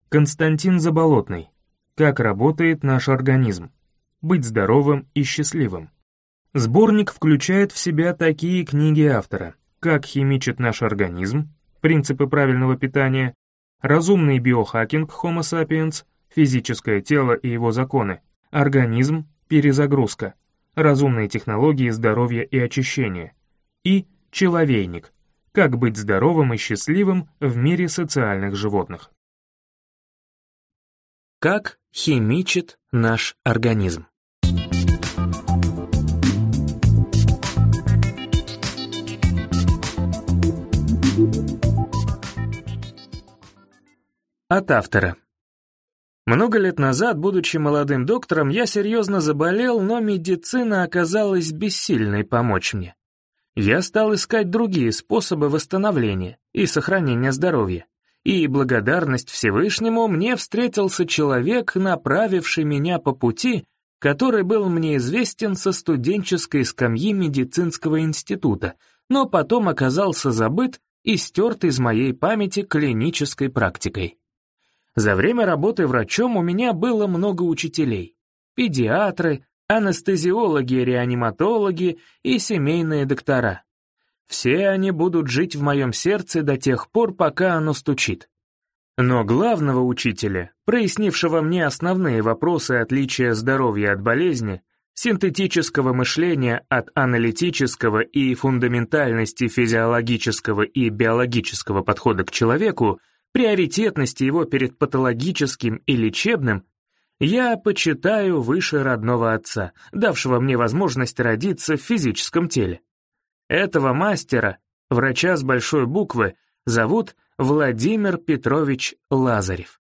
Аудиокнига Как работает наш организм: быть здоровым и счастливым | Библиотека аудиокниг